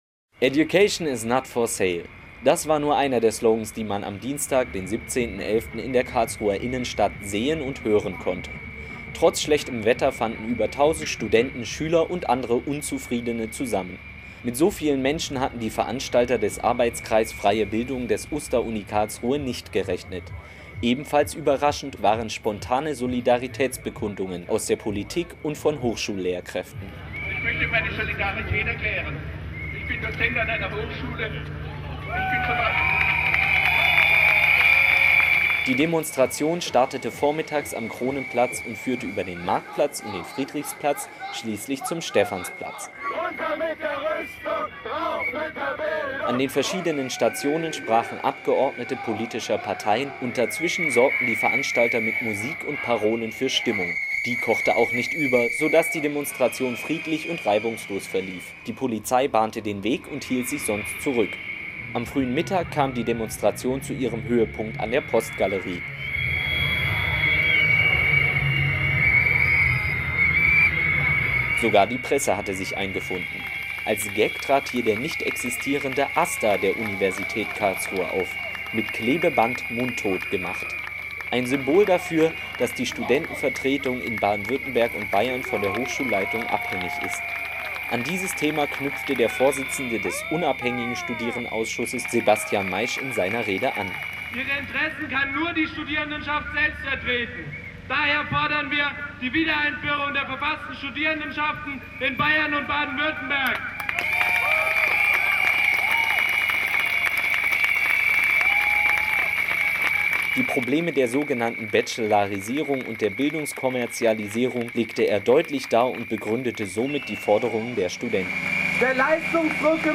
Am 17.11. demonstrierten über 1000 Studenten und Schüler in der Karlsruher Innenstadt. Sie forderten Verbesserungen im Bildungssystem unter dem Motto "Bildungsstreik 2009 - Wir machen weiter".